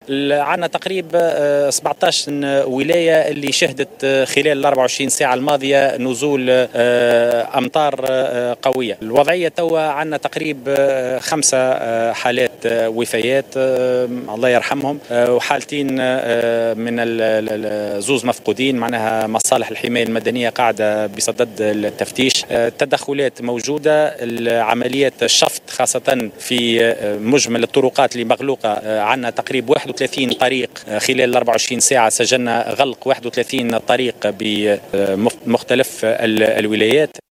قال وزير الداخلية هشام الفراتي خلال زيارة ميدانية إلى تونس الكبرى صباح اليوم الخميس 18 أكتوبر 2018، إن 17 ولاية شهدت أمطار غزيرة خلال الـ24 ساعة الماضية.